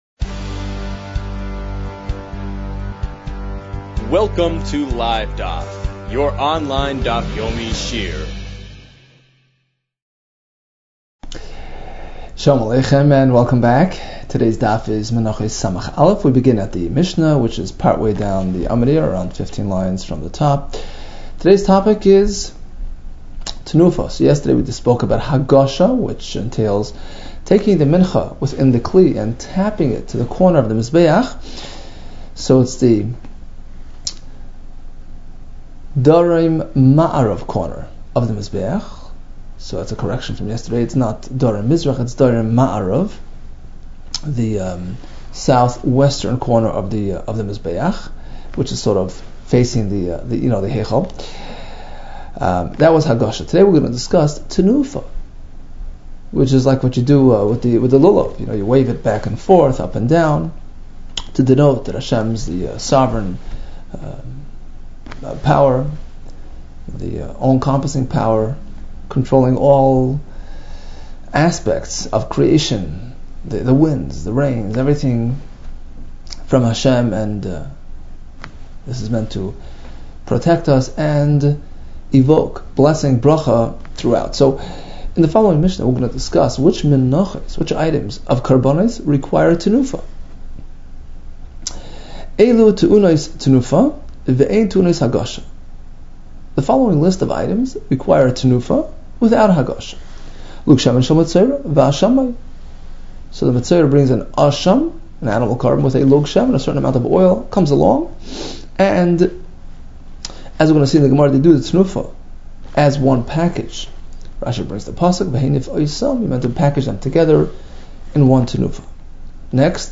Menachos 60 - מנחות ס | Daf Yomi Online Shiur | Livedaf